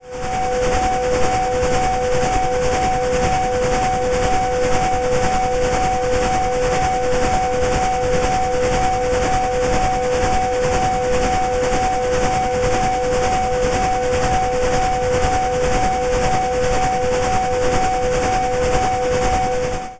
sirens
Pink noise requires a relatively high power to be noticeable, so let's apply a notch filter at 4 kHz to make it more noticeable per power. We want our noise to have a sense of urgency, so let's add 10 Hz tremolo. The result sounds kind of like a steam train.
We can do that by adding some sine waves, say, 500 Hz and 750 Hz.
Finally, let's add 2 Hz tremolo to reduce perceptual satiation.
That's using heavy tremolo with offset phases on the sine waves; I'm not sure if that's optimal, but it seems more "siren-like".
vehicle_alert_sound.mp3